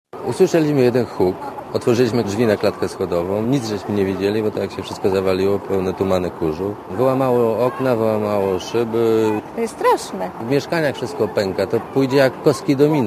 (RadioZet) Źródło: (RadioZet) Komentarz audio (100Kb) Początkowo policja twierdziła, że osunęła się ściana budynku mieszkalnego przy ul.